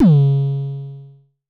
Index of /m8-backup/M8/Samples/Drums/LookIMadeAThing Kicks - Vermona Kick Lancet/Distortion Kicks/Dist Kick - Precise
Dist Kicks 25 - C#3.wav